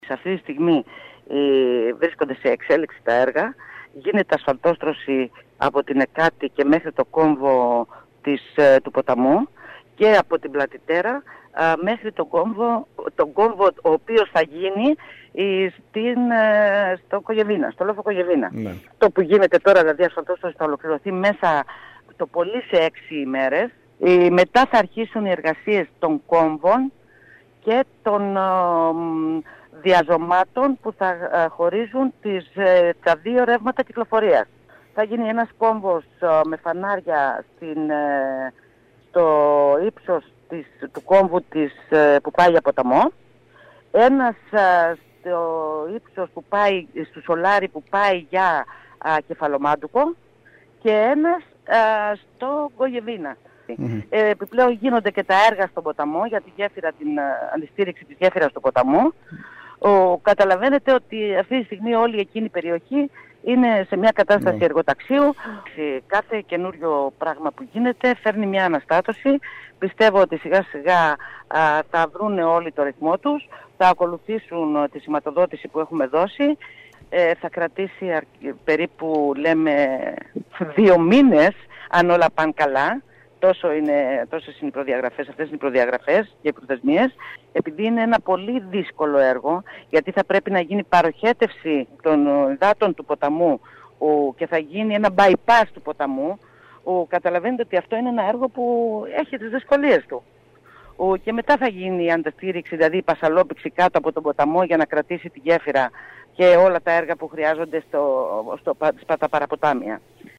Στη συντήρηση του υφιστάμενου οδικού δικτύου θα διατεθούν γύρω στα 20 εκ ευρώ από τα 33 εκατομμύρια που δόθηκαν ως έκτακτη ενίσχυση στην Περιφέρεια Ιονίων Νήσων από το ΠΔΕ. Αυτό τόνισε μιλώντας στο σταθμό μας η Αντιπεριφερειάρχης Κέρκυρας Νικολέττα Πανδή.